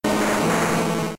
Cri de Dracaufeu K.O. dans Pokémon Diamant et Perle.